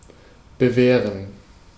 Ääntäminen
US : IPA : [ˈɑːɹ.mɚ]